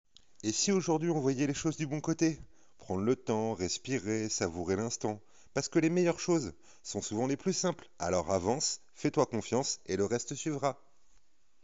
Voix off souriante naturelle